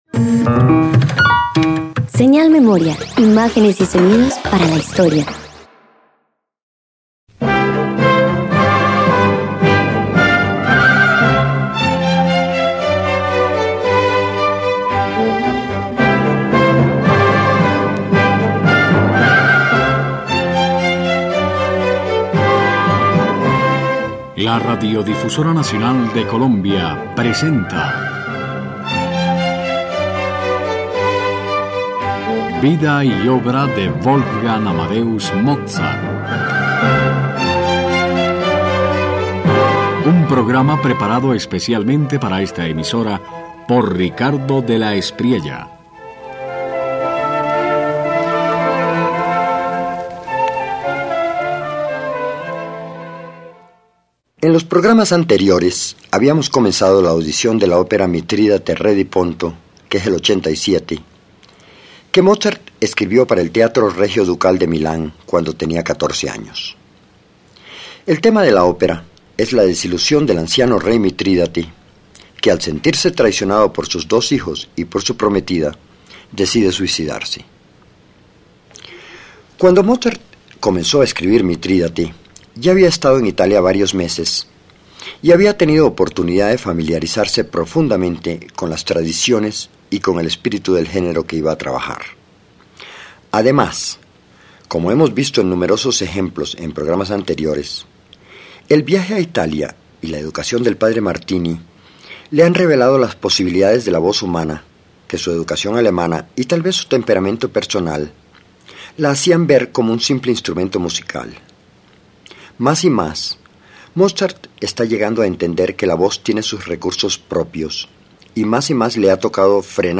Aspasia y Sifare se descubren en el umbral del deber. En el adagio “Lungi da te”, el diálogo entre la voz y la trompa parece el suspiro de dos almas condenadas a separarse. Mozart capta la nobleza del sacrificio y la convierte en belleza pura.